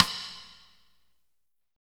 SNR P C S13R.wav